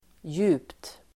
Uttal: [ju:pt]